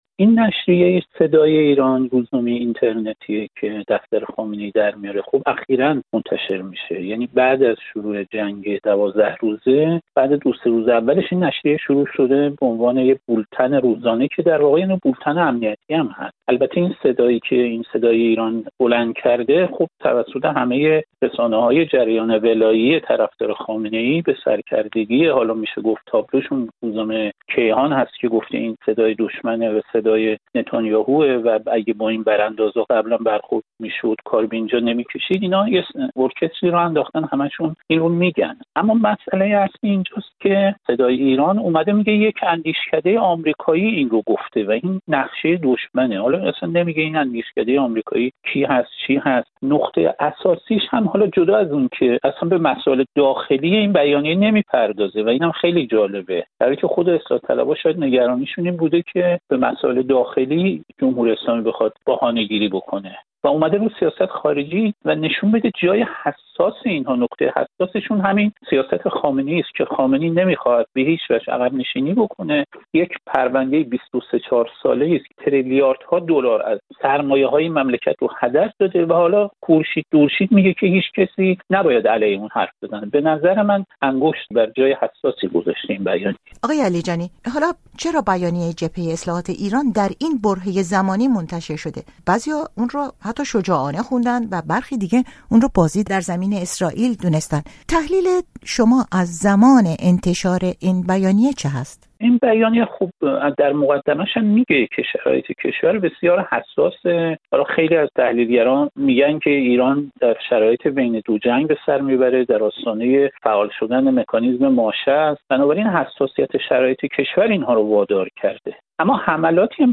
در گفت‌وگو با رضا علیجانی، تحلیلگر مسائل ایران ساکن پاریس، دیدگاه او را دربارهٔ این بیانیه و واکنش‌ها به آن پرسیدیم.